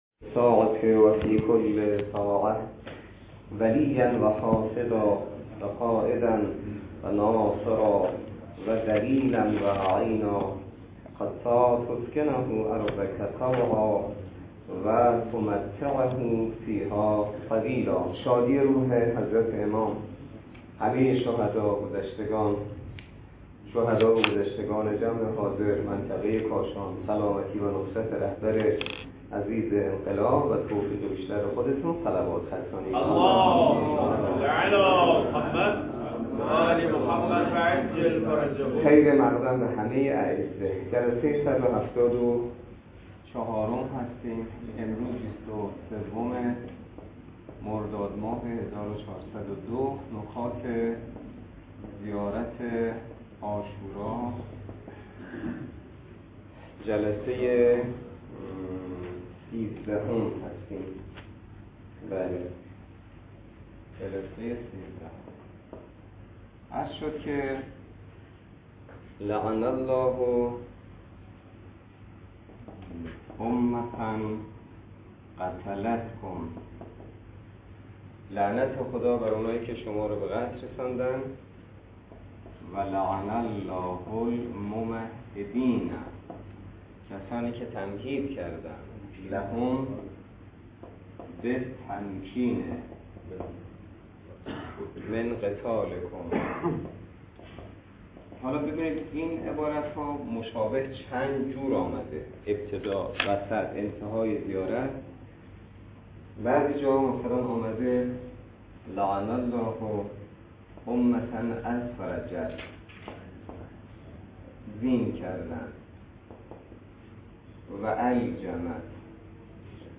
درس فقه الاجاره نماینده مقام معظم رهبری در منطقه و امام جمعه کاشان - جلسه صد و هفتاد و چهار .